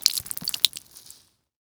blood.wav